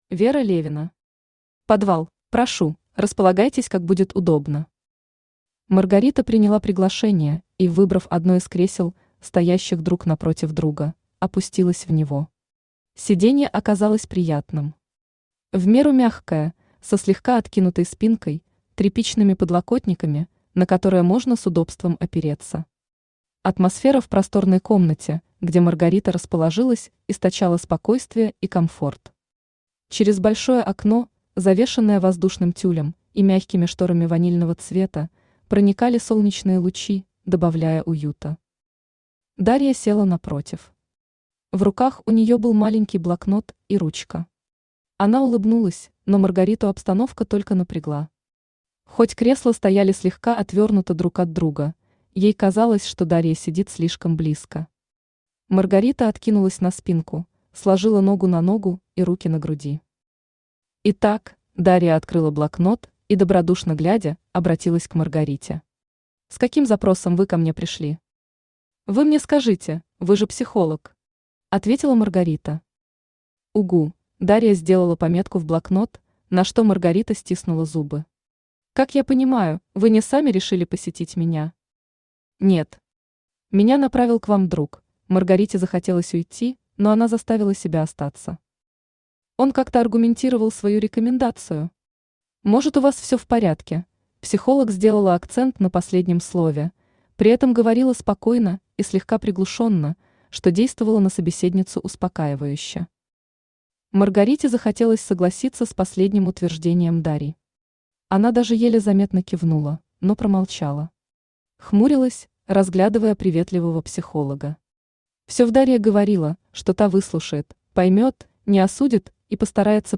Aудиокнига ПодВал Автор Вера Левина Читает аудиокнигу Авточтец ЛитРес.